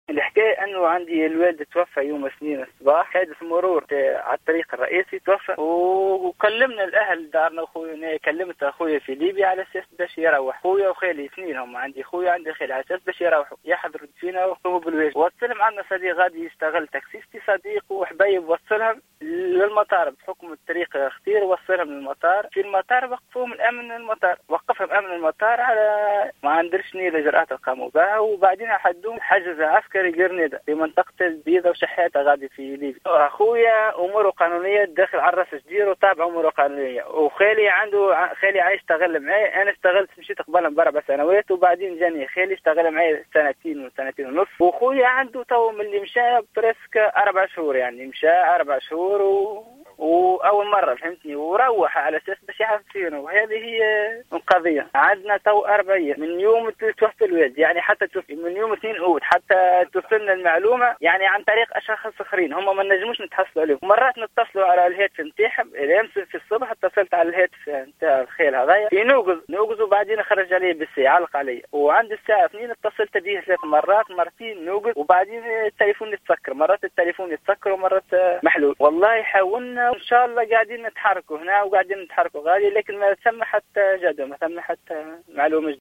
في اتصال هاتفي مع الجوهرة أف أم